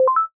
beep2.wav